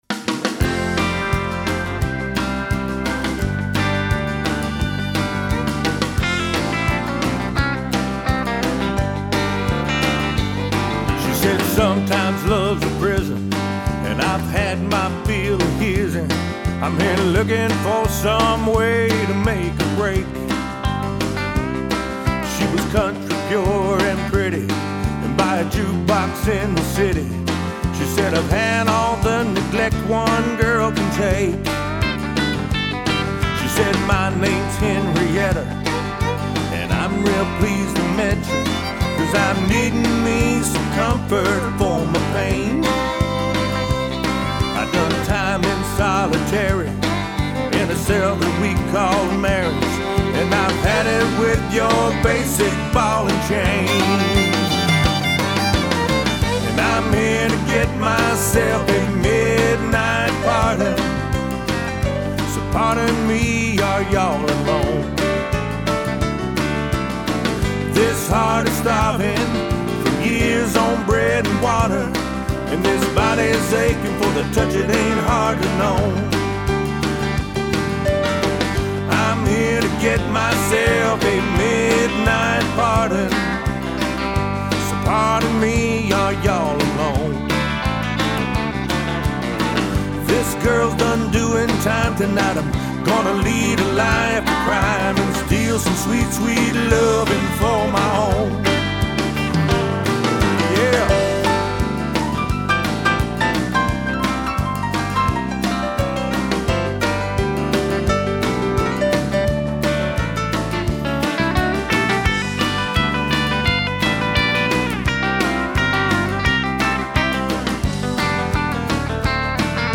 Our “after” version: